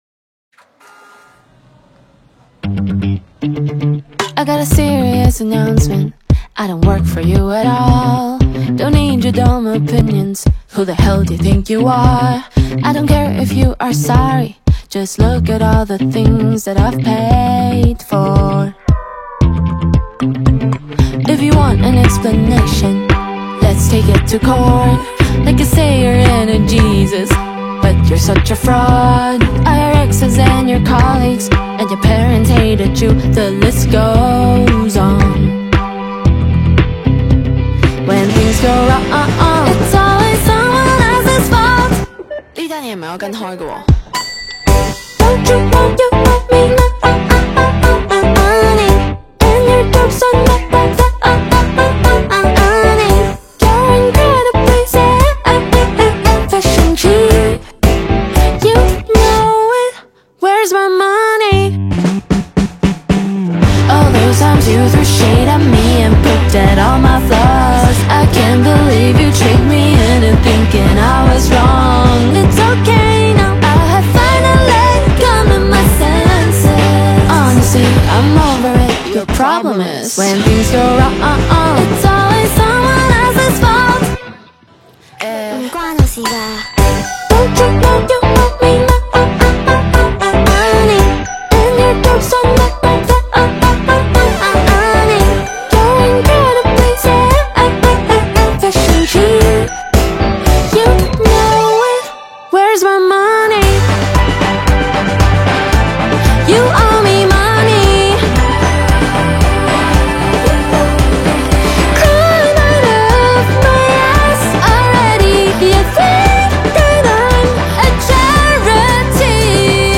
Hip Hop